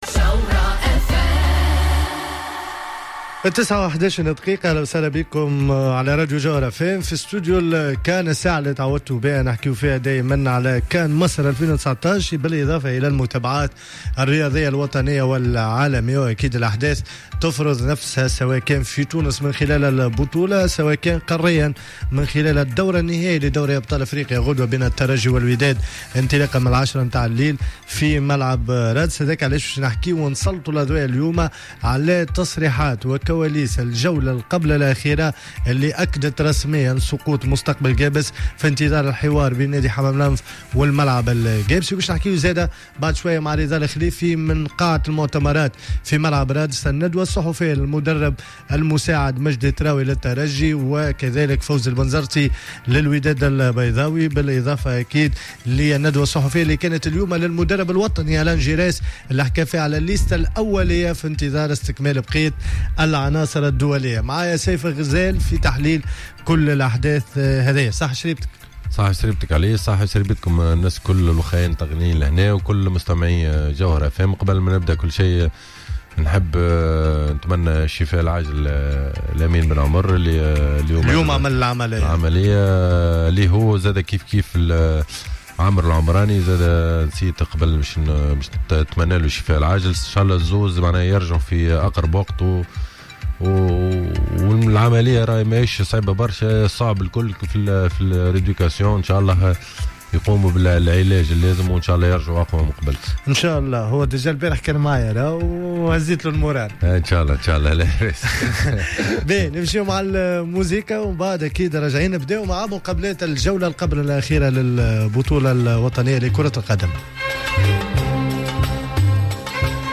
تطرقت حصة "استوديو الكان" ليوم الخميس 30 ماي 2019 لرؤية في القائمة الأولية للمنتخب الوطني التونسي قبل المشاركة في نهائيات كأس إفريقيا مصر 2019 بالإضافة لعودة لمباريات الجولة 25 من بطولة الرابطة المحترفة الأولى لكرة القدم و تغطية للندوة الصحفية الخاصة بمقابلة الترجي الرياضي التونسي و الوداد البيضاوي المغربي مباشرة من ملعب رادس.